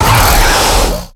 attack_hit_0.ogg